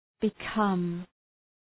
{bı’kʌm}